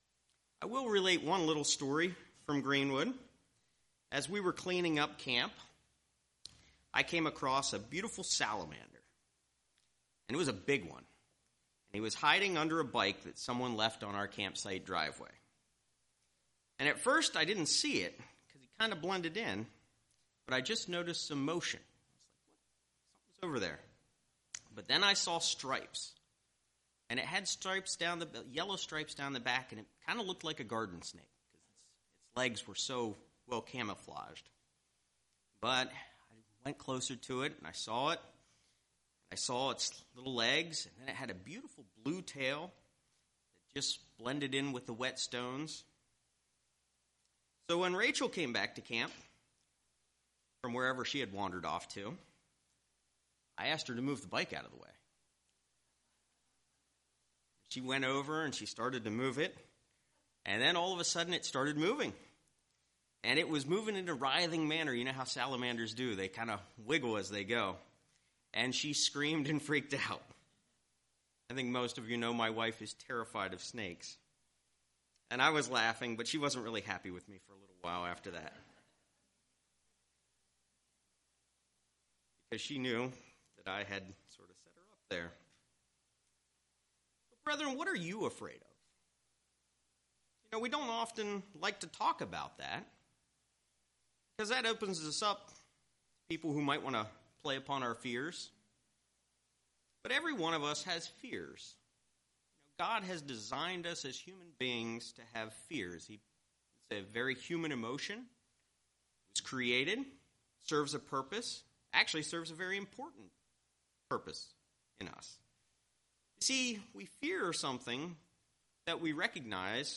Note - We were having audio issues for the first 38 seconds.